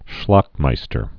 (shlŏkmīstər)